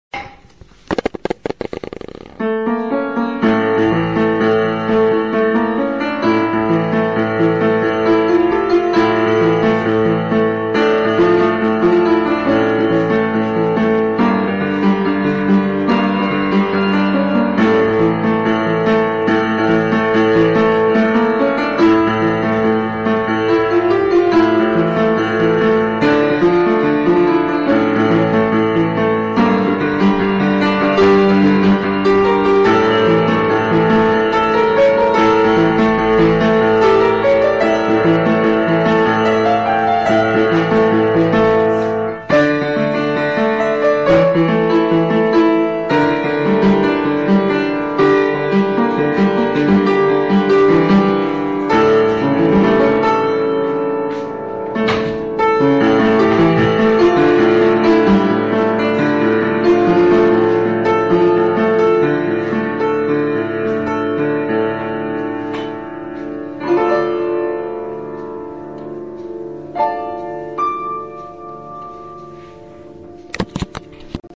מקצב מזרחי.